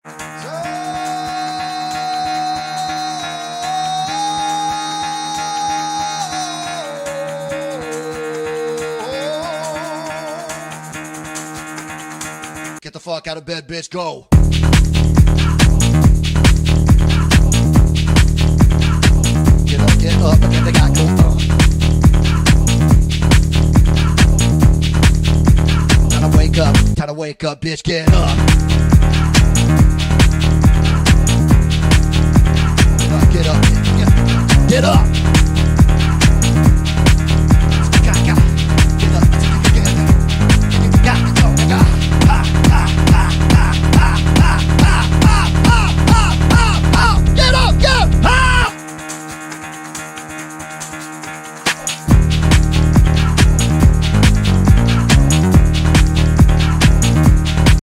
your-new-morning-alarm.ogg